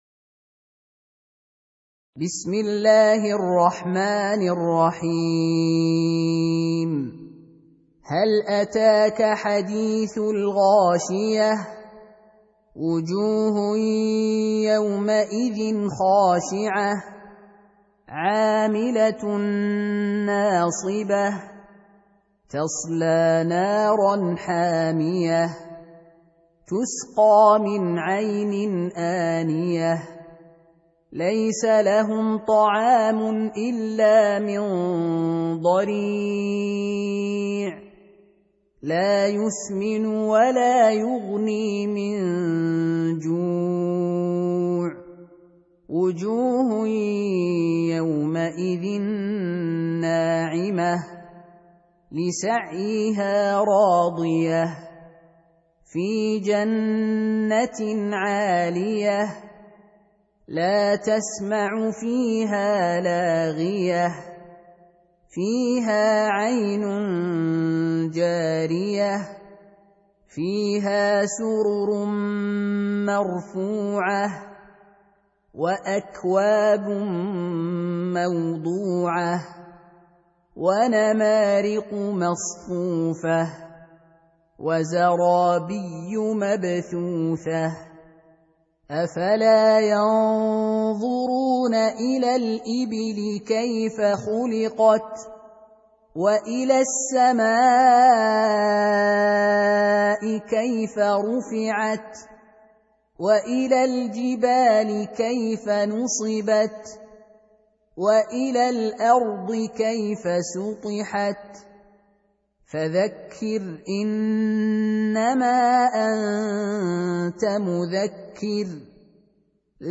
Surah Repeating تكرار السورة Download Surah حمّل السورة Reciting Murattalah Audio for 88. Surah Al-Gh�shiyah سورة الغاشية N.B *Surah Includes Al-Basmalah Reciters Sequents تتابع التلاوات Reciters Repeats تكرار التلاوات